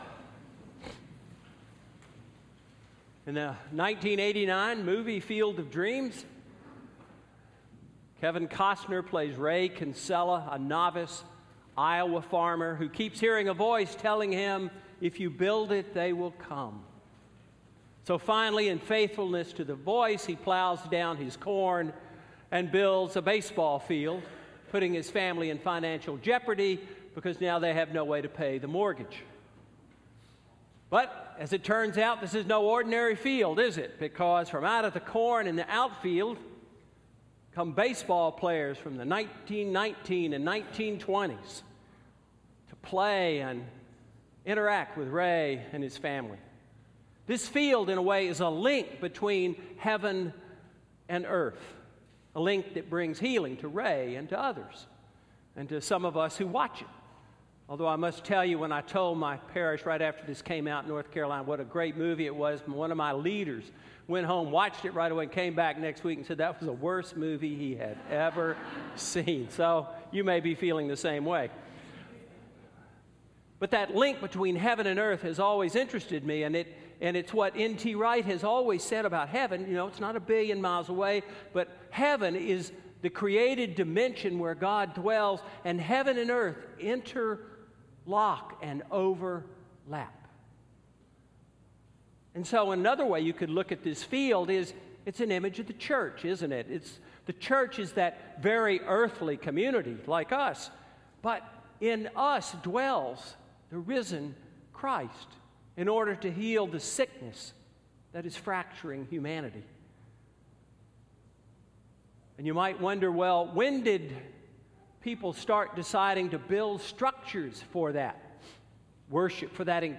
Sermon–Living Stones–May 1, 2016 – All Saints' Episcopal Church